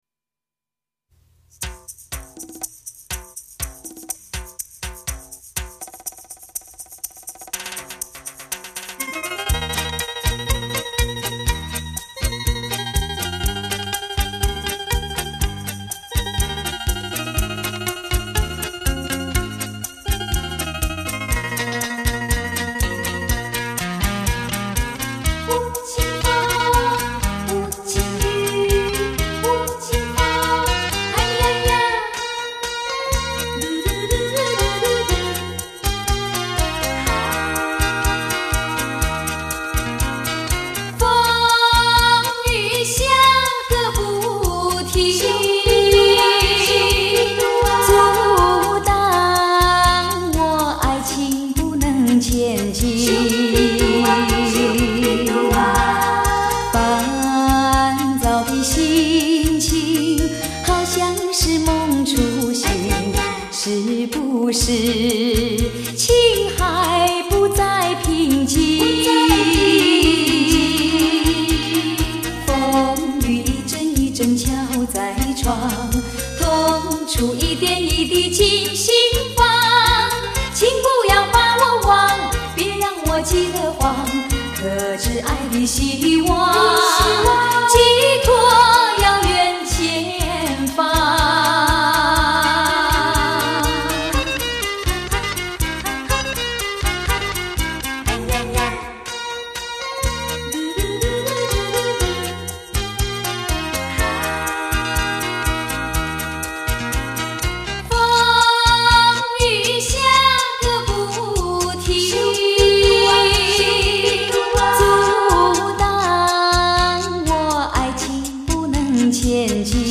录音：台北乐韵录音室 广州国光录音棚